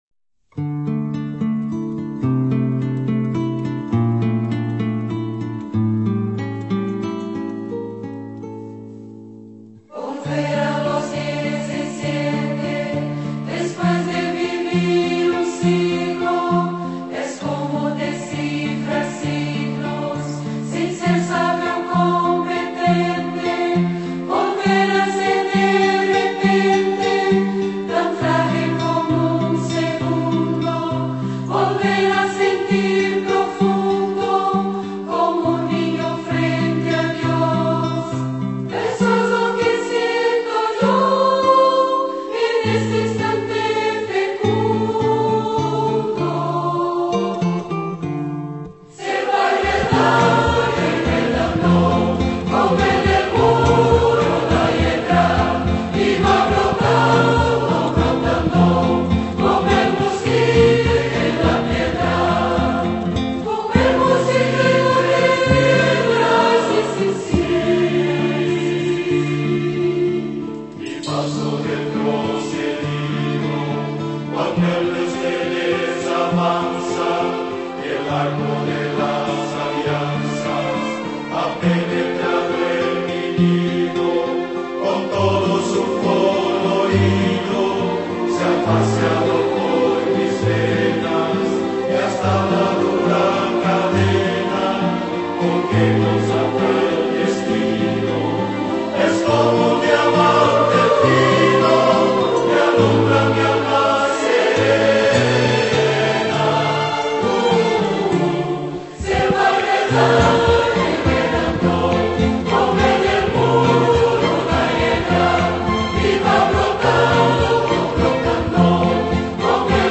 Pianistas